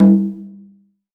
CONGA HI.wav